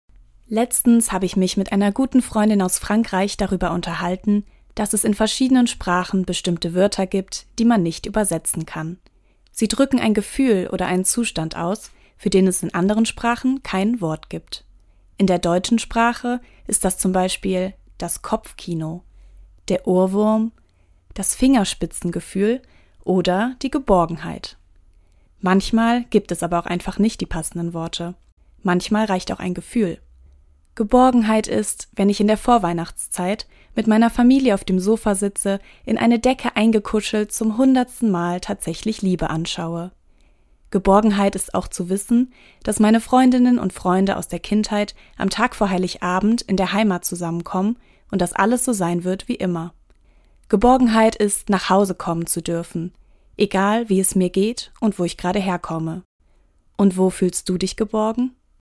Autorin und Sprecherin ist